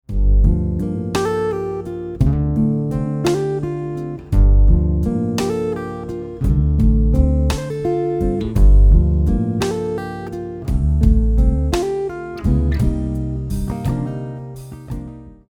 🎵 Style: Smooth R&B
🎵 Key Center: G minor
🎵 Tempo: 85 BPM
🎵 Time Signature: 6/8
🎵 Mood: Mellow, Soulful, Warm